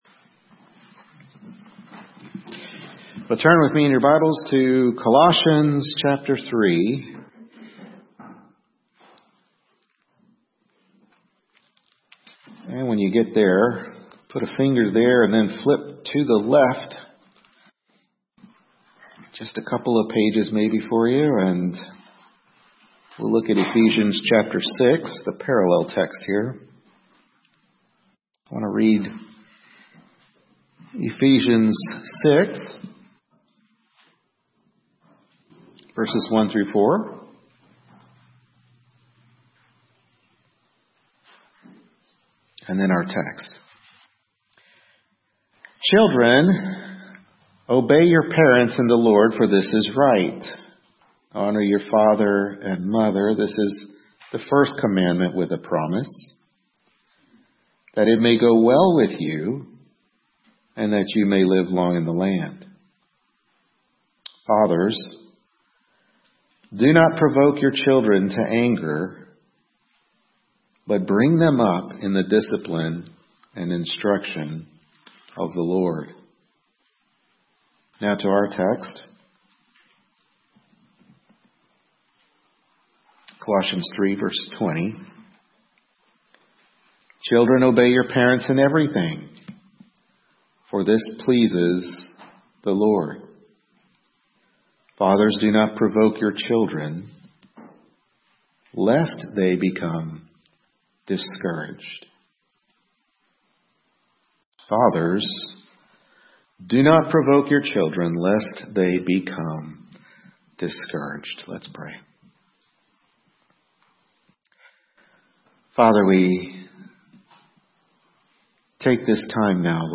Service Type: Morning Worship Service